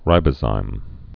(rībə-zīm)